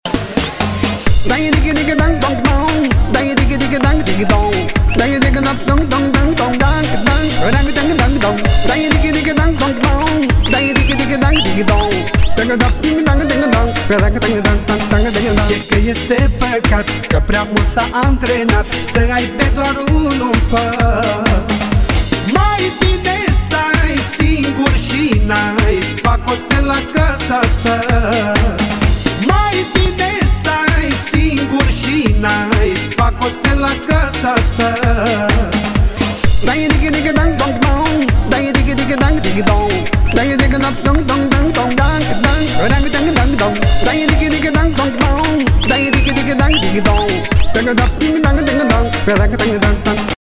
• Качество: 256, Stereo
веселые
цыганские
румынские
песни румынских цыган